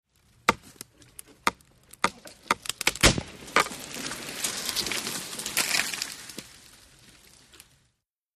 SmlTreeStressCreak PE698601
IMPACTS & CRASHES - FOLIAGE TREE: EXT: Small tree falling, stress creaks, stump crack & fall to the ground.